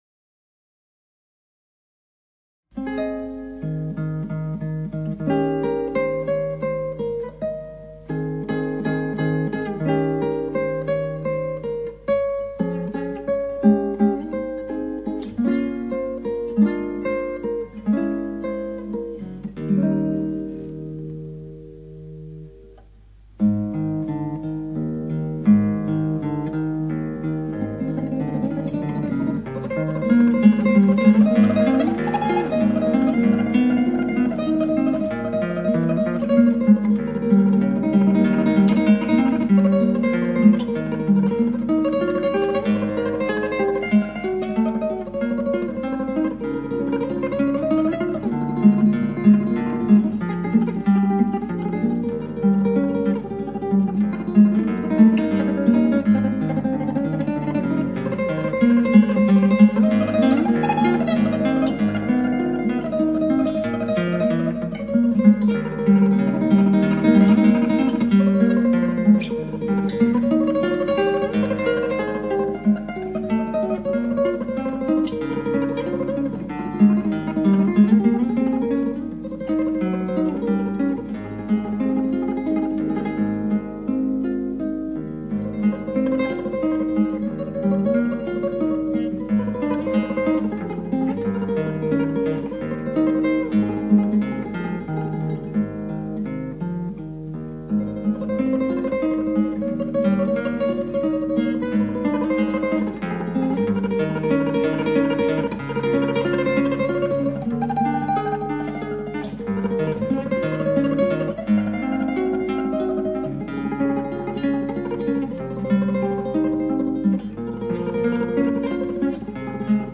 クラシックギター　ストリーミング　コンサート
これ、バリオスのトレモトロの曲なのですが。
ヴィブラートもかけやす～いでつ。